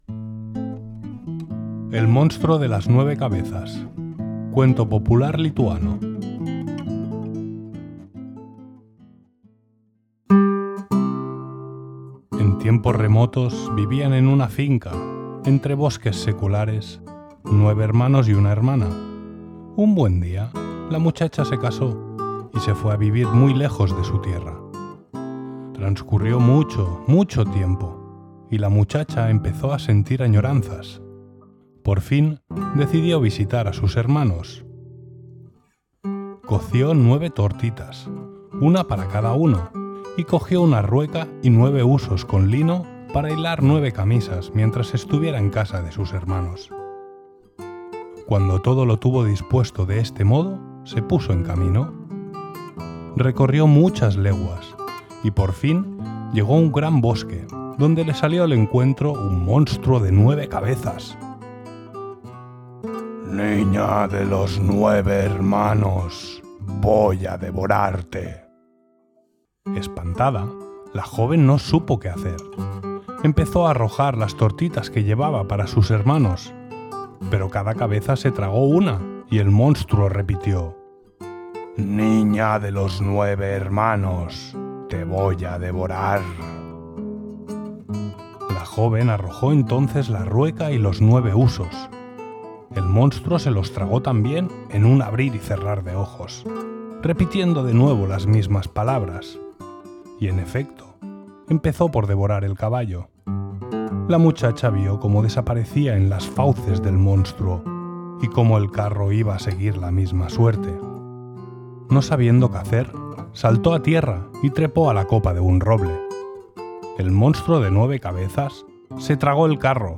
👹-el-monstruo-de-las-nueve-cabezas-🐉-cuento-popular-lituano-🎻-narrado-en-espanol.mp3